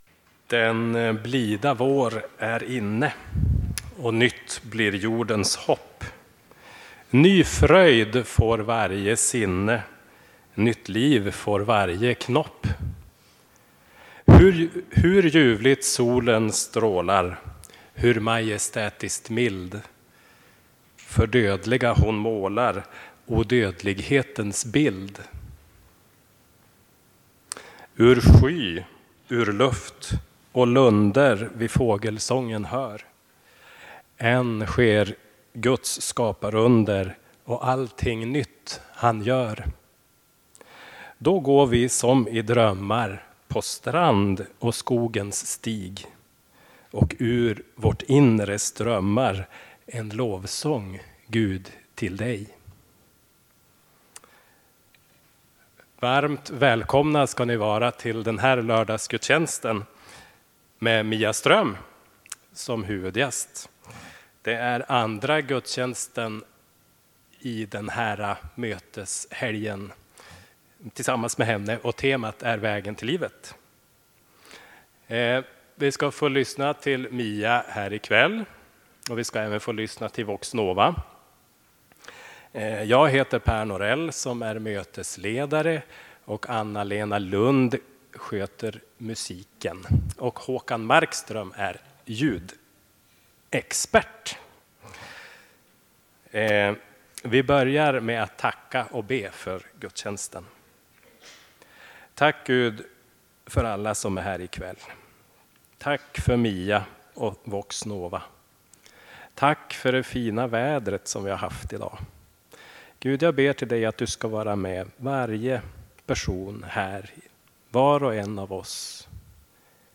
Efter sommaruppehållet startade höstterminen med en iCentrum-gudstjänst. Den handlade om missionsuppdraget som vi delar med alla troende.
Lyssna gärna till Gudstjänsten!